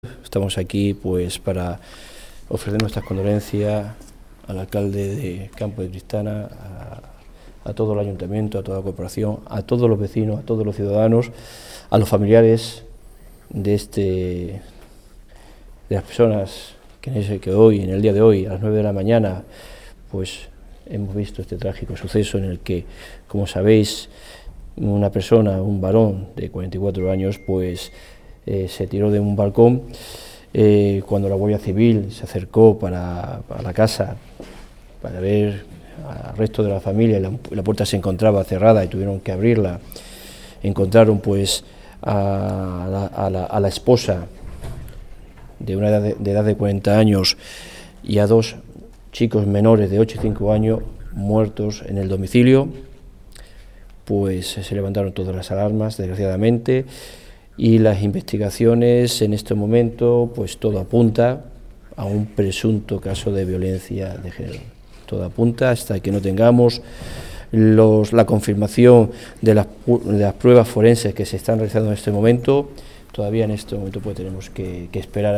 Declaraciones Delegado del Govierno en CLM 1